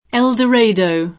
eN dër$idëu